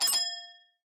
ArrowBullseye.wav